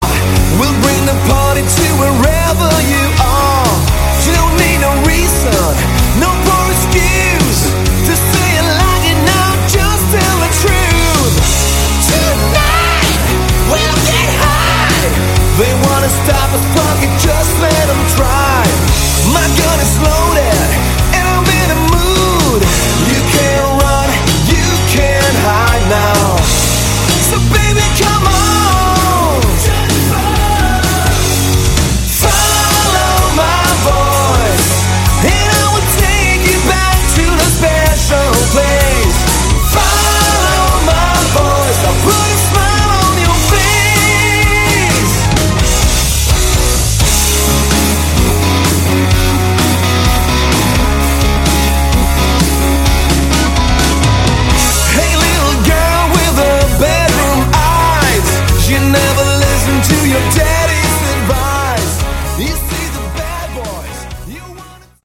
Category: Hard Rock
drums
bass
guitars
vocals
keyboards